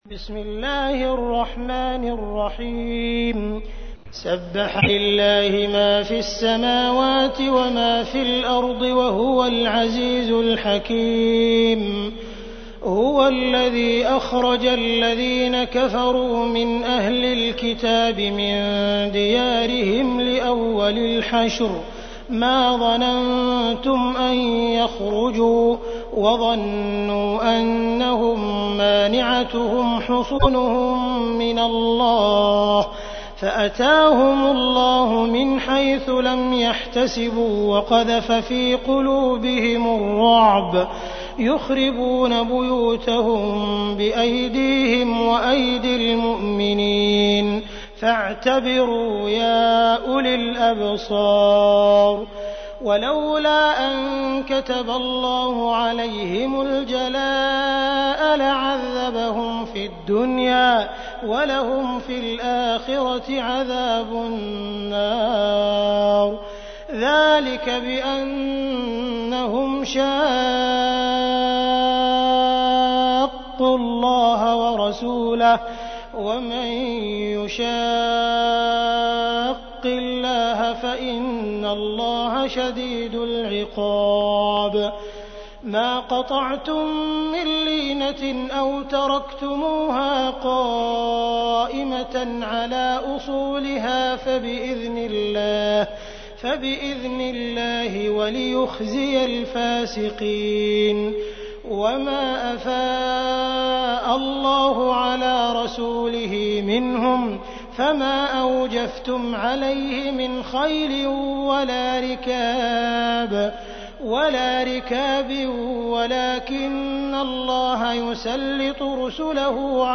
تحميل : 59. سورة الحشر / القارئ عبد الرحمن السديس / القرآن الكريم / موقع يا حسين